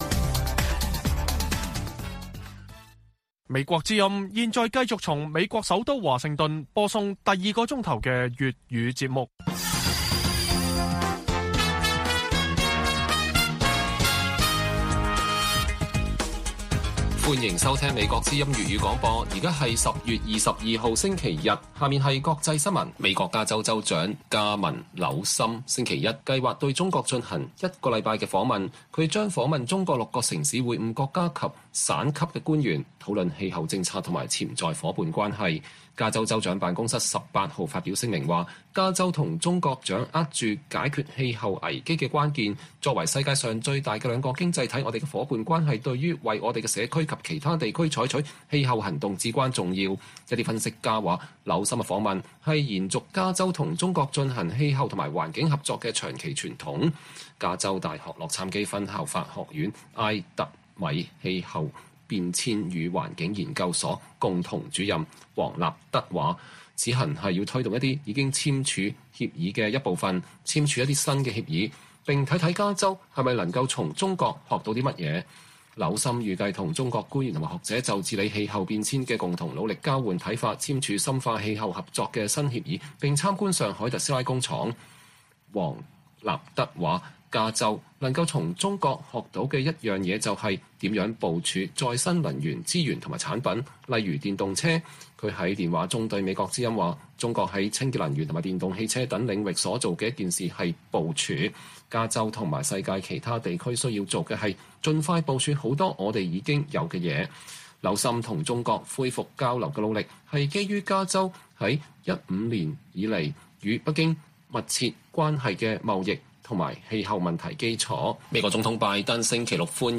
粵語新聞 晚上10-11點 : 澳洲總理將於11月訪華並會見習近平